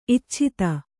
♪ icchita